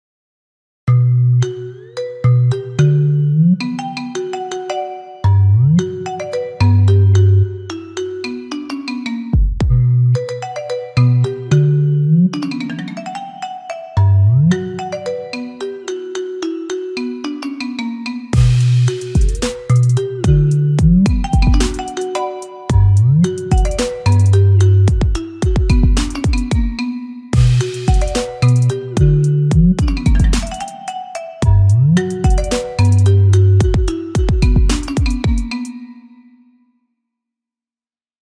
• Качество: 128, Stereo
без слов
приятные
расслабляющие
ремикс
маримба
биты